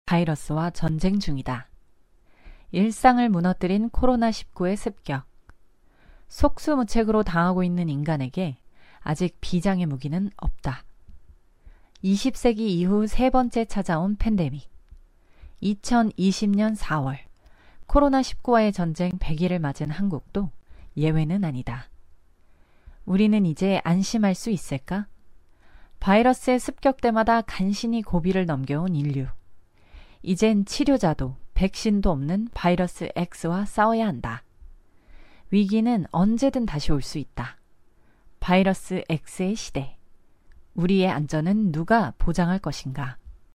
韩语样音试听下载
韩语配音员（女4）